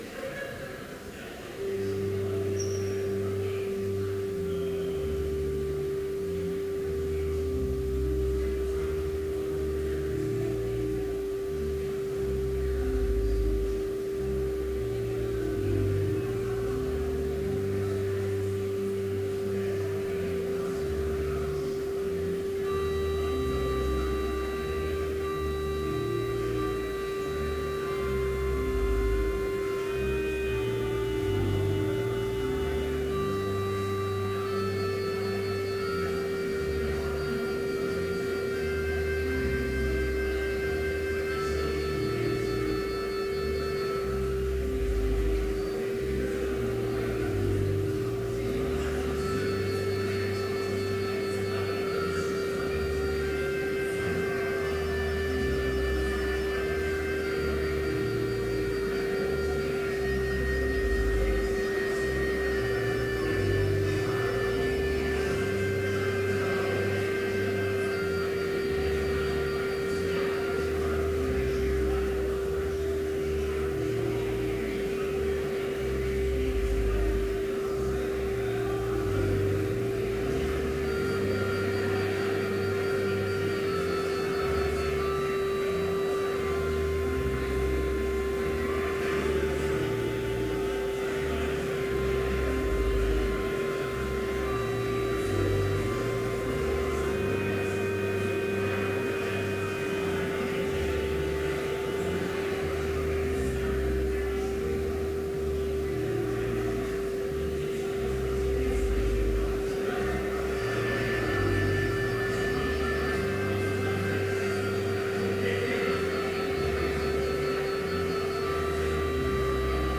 Complete service audio for Chapel - April 7, 2014